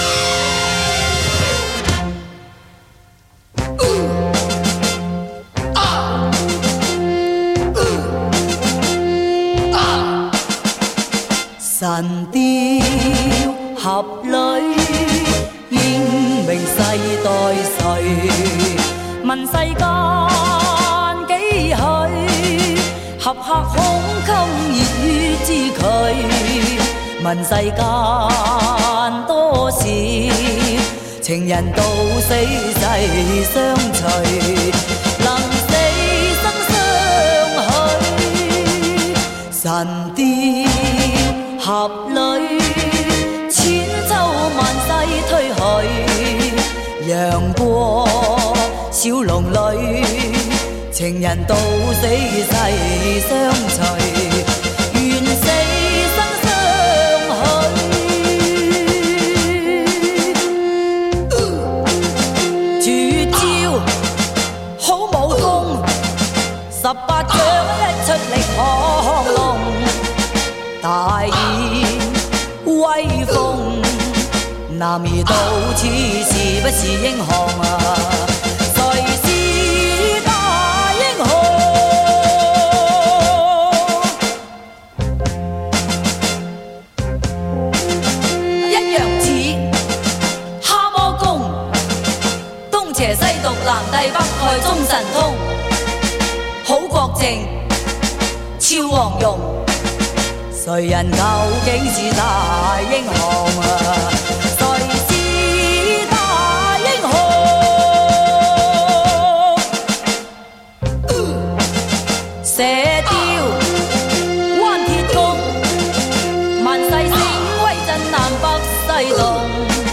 [华语怀旧]
回味从小到大的磁性声音 惟有黑胶原版CD